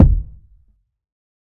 TC Kick 04.wav